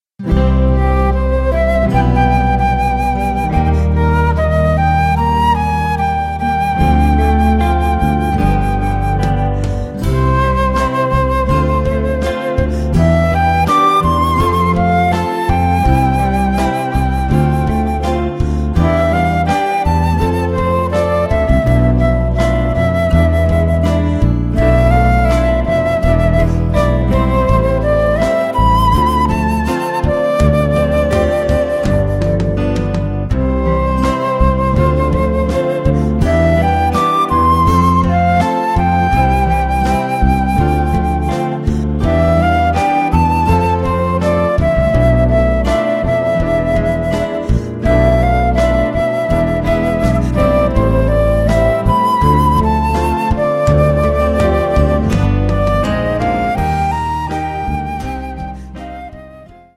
Gattung: Flöte mit Online-Audio
Besetzung: Instrumentalnoten für Flöte